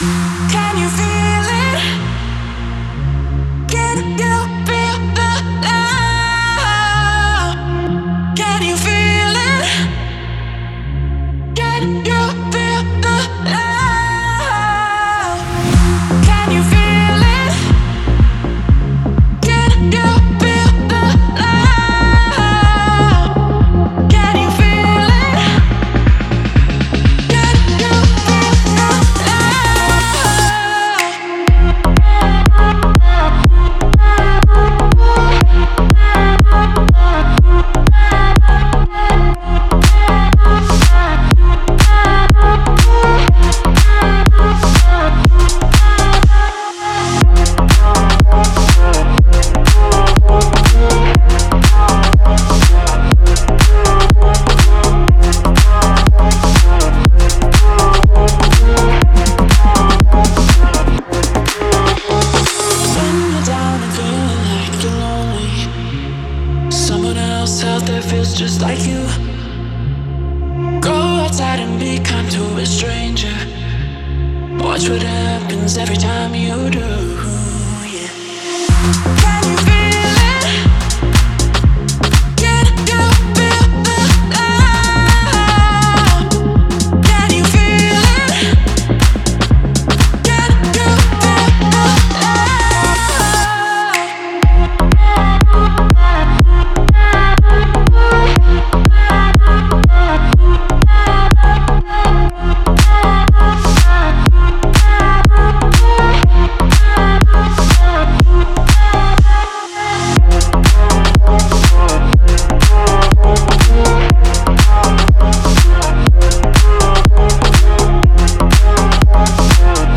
мягкий вокал, который идеально передает эмоции.